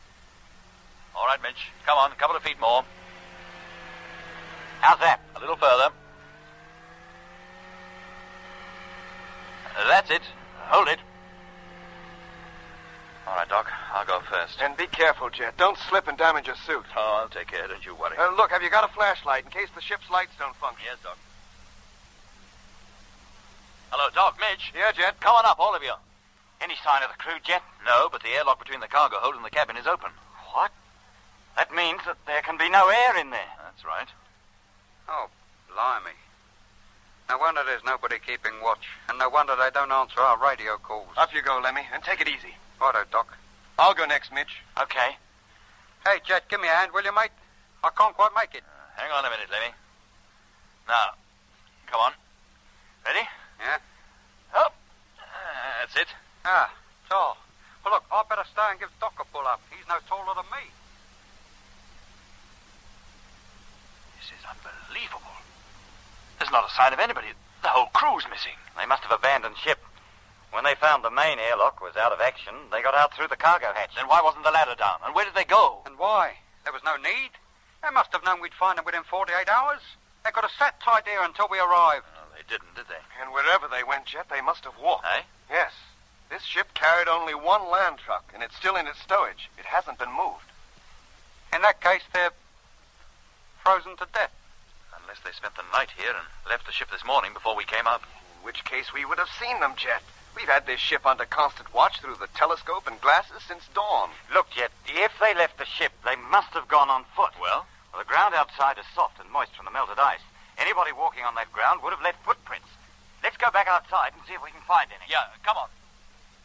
Toch duurt de Britse versie (met 1 minuut 56 seconden, tegenover de Nederlandse versie met 2 minuut en 49 seconden)  bijna een minuut korter dan die Nederlandse versie.